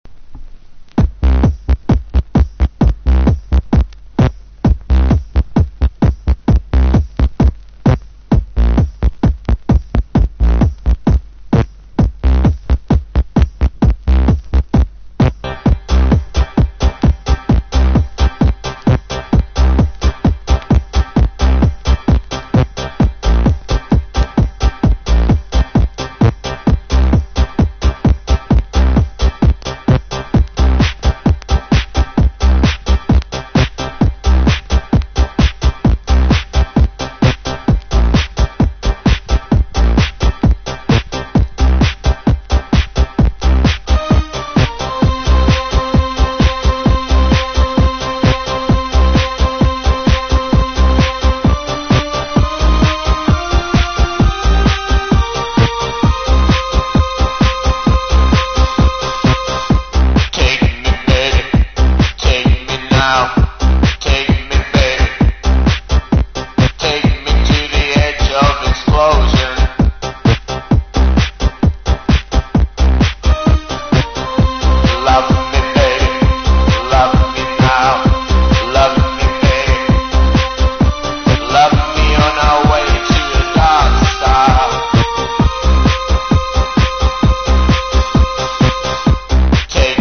多くのDJがプレイしていた大ヒットテクノトラック！
TECHNO / DETROIT / CHICAGO